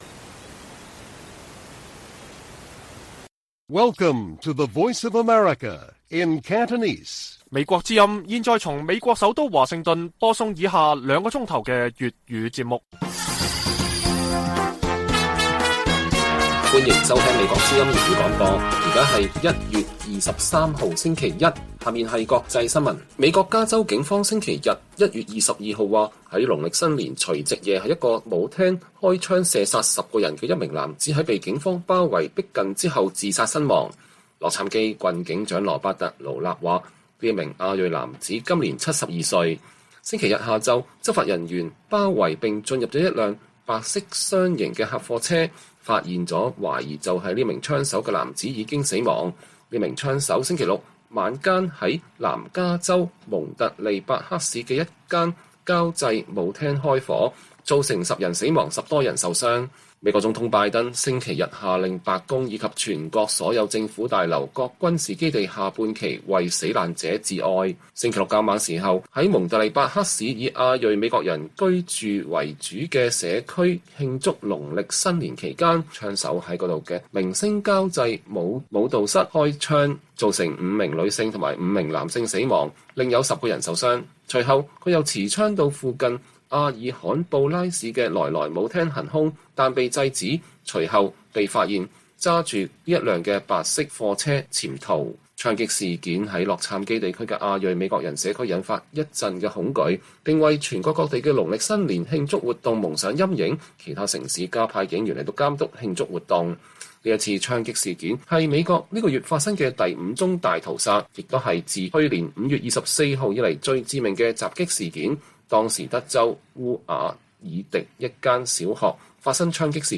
粵語新聞 晚上9-10點 : 港府擬規管眾籌展開立法諮詢 各界憂影響政黨網媒文創營商環境